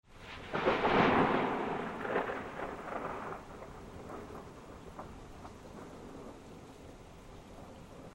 TRUENOS
Tonos EFECTO DE SONIDO DE AMBIENTE de TRUENOS
Truenos.mp3